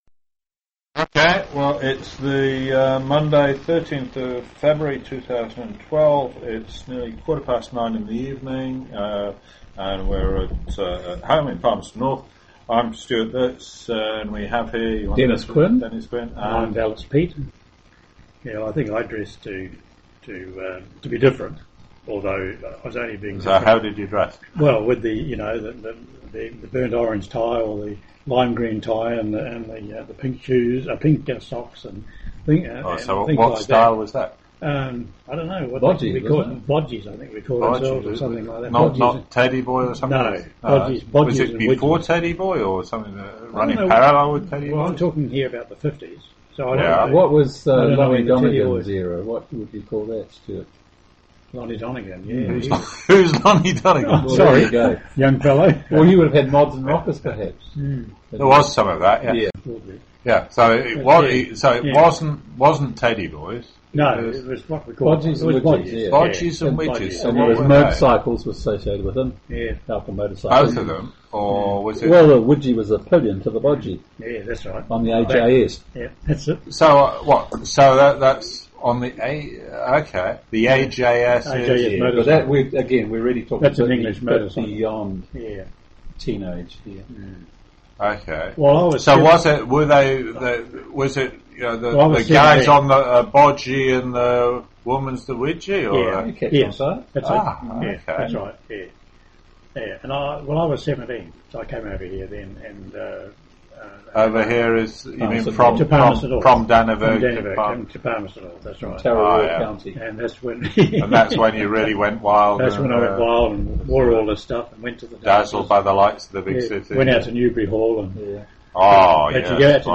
Teen years - oral interview - Manawatū Heritage
Summary of interview